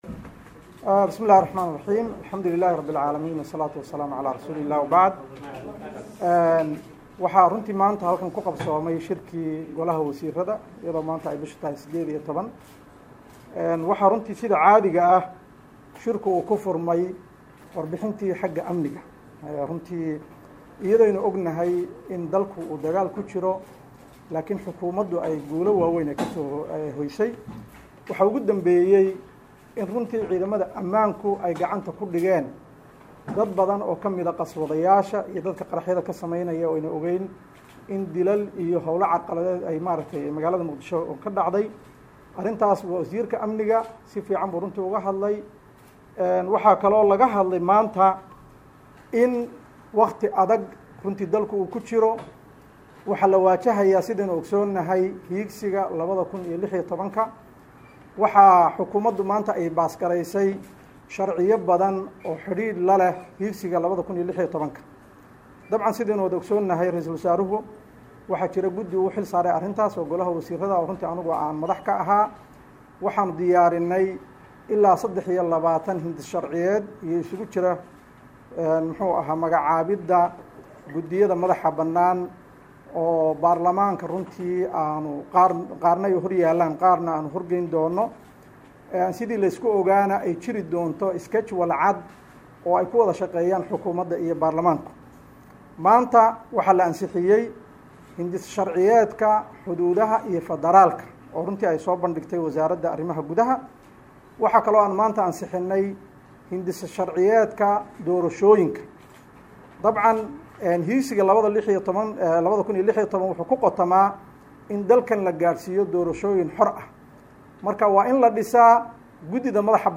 DHAGEYSO: Shirkii Jaraa'id ee Golaha Wasiirrada Somalia